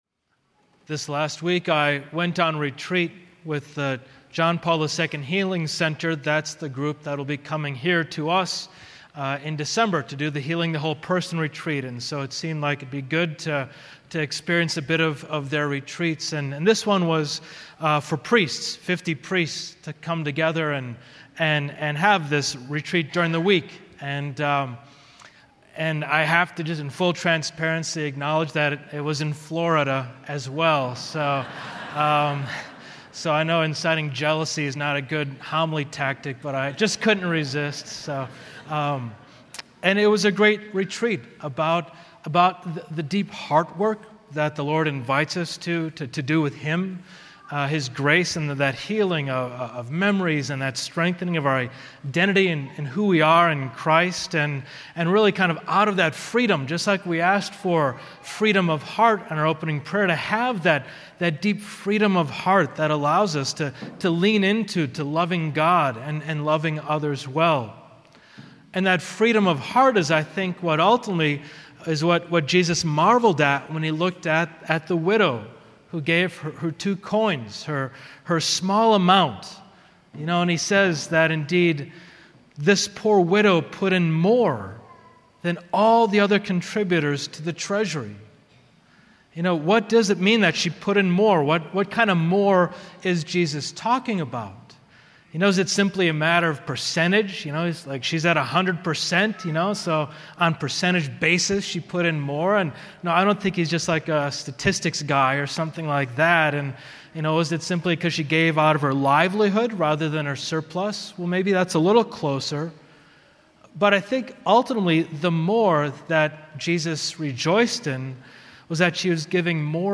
32nd SOT Homily